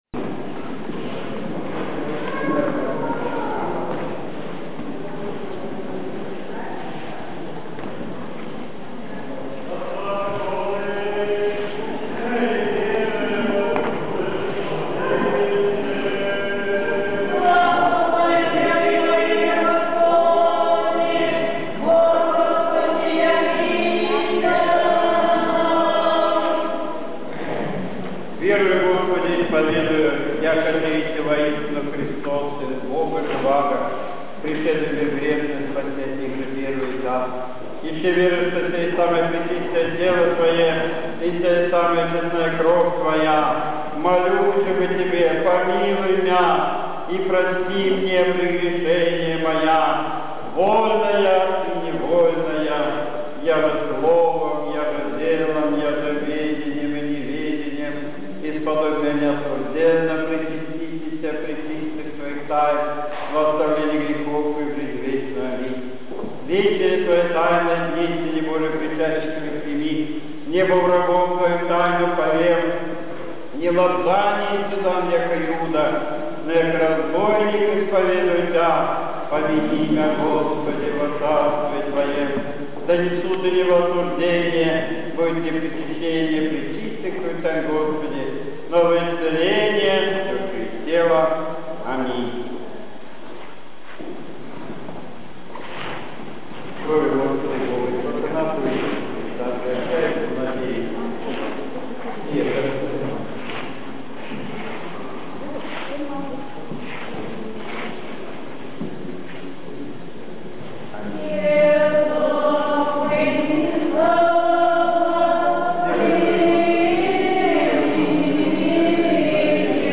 Празднование  Преображения  Господня
Божественная  литургия